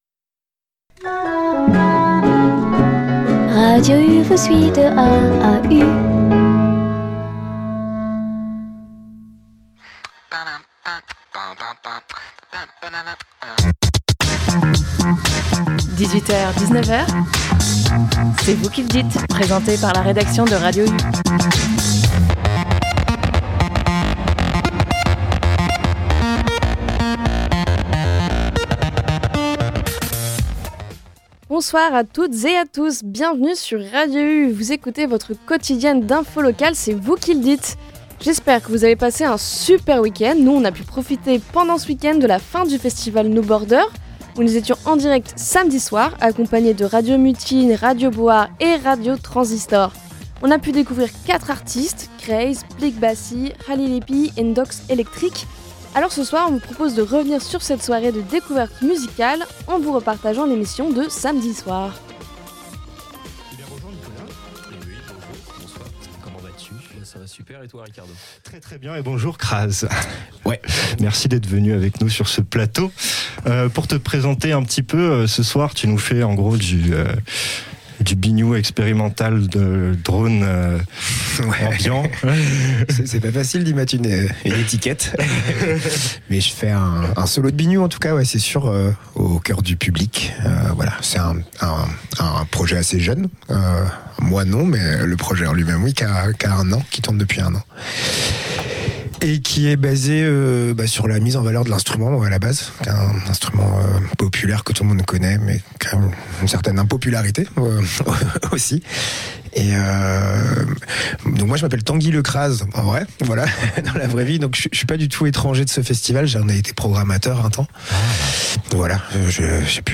Dans cette émission, une rediffusion du direct de Radio U lors de l’édition 2024 du festival noBorder.